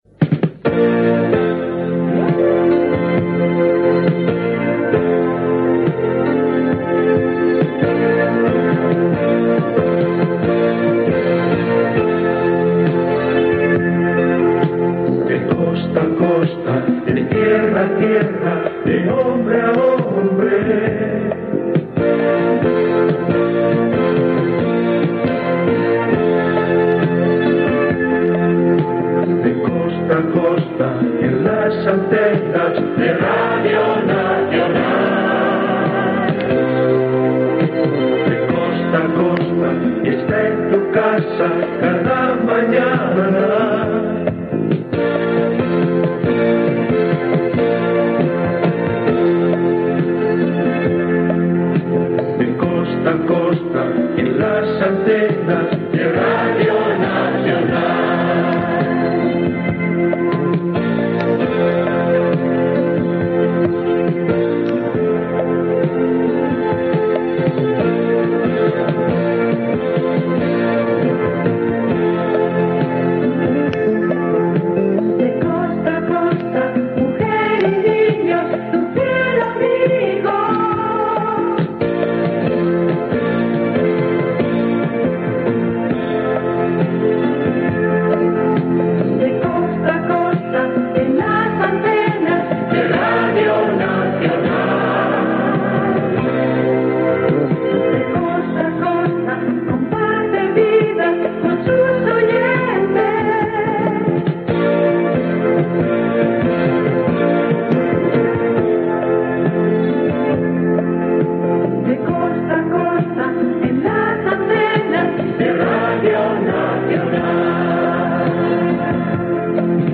Indicatiu cantat del programa
Info-entreteniment